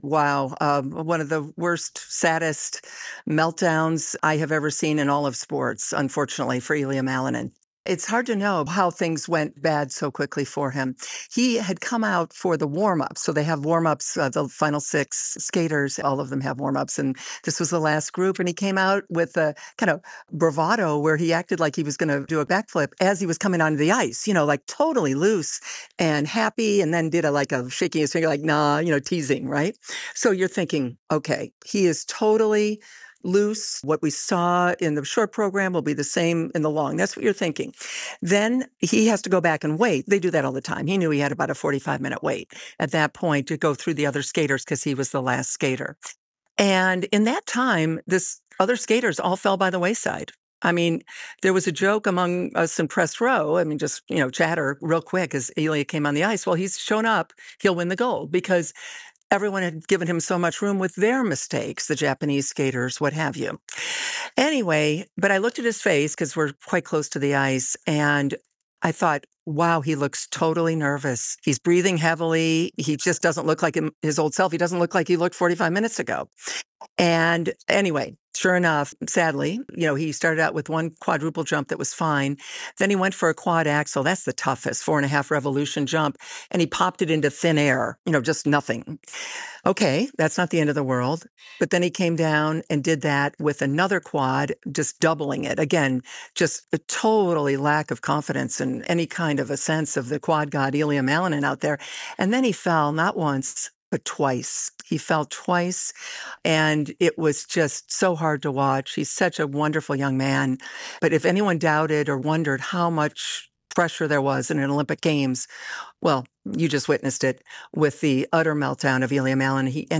USA Today Sports columnist and co-host of the 'Milan Magic' podcast Christine Brennan speaks to WTOP about Malinin's performance